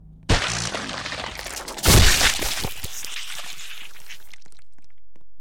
pursuer stalk ability Meme Sound Effect
pursuer stalk ability.mp3